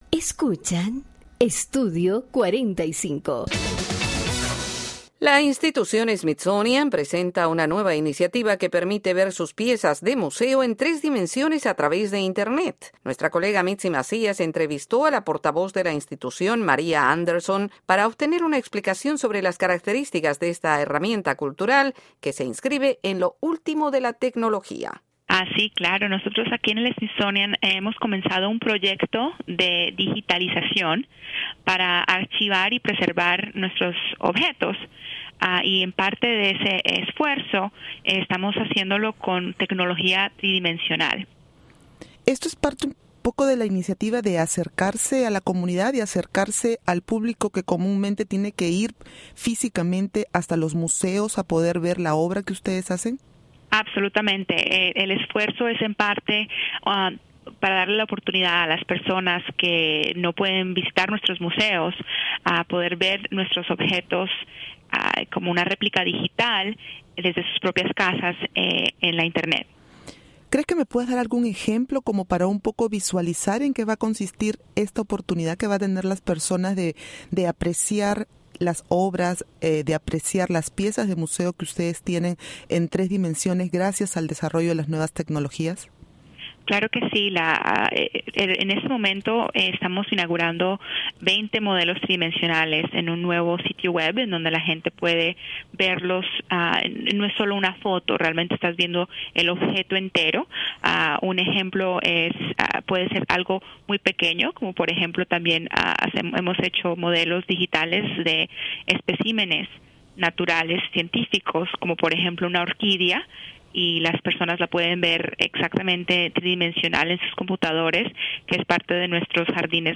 Entrevista Smithsonian piezas de museo en 3D